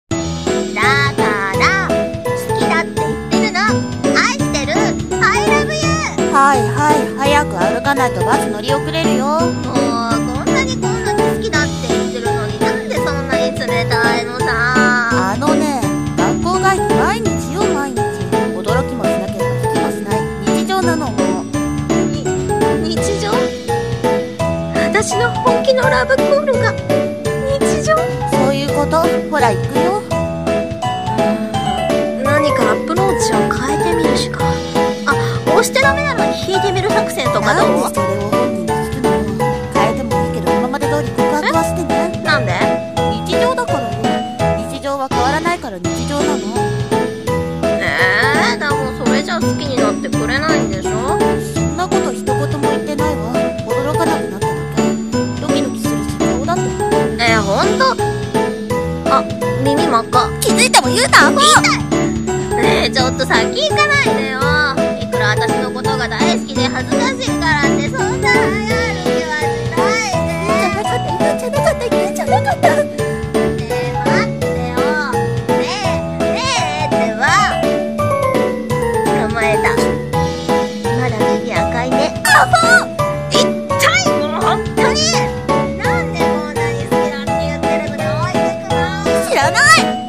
声劇台本【特別じゃない帰り道